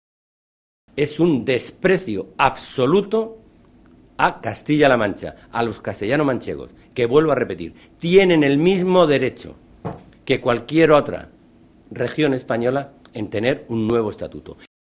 Cortes de audio de la rueda de prensa
Audio Alonso r. prensa Congreso